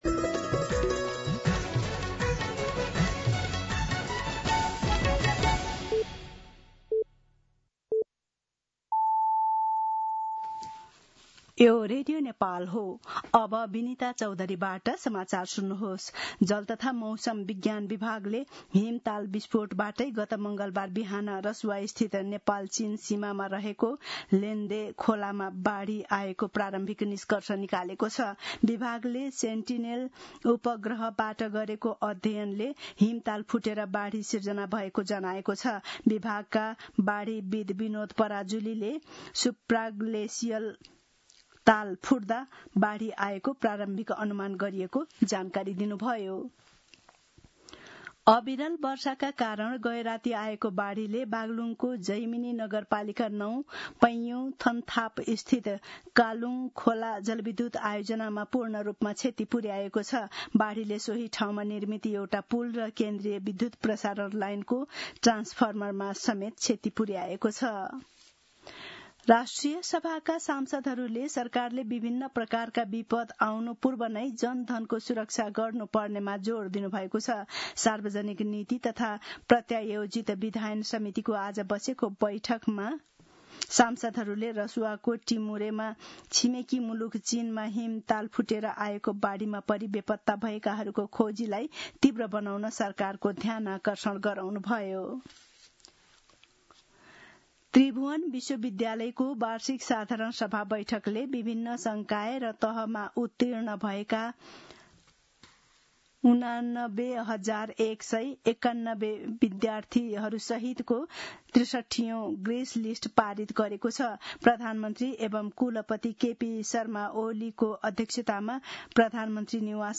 दिउँसो ४ बजेको नेपाली समाचार : २६ असार , २०८२
4-pm-News-3-26.mp3